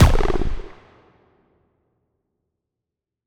TM88 FunkKick2.wav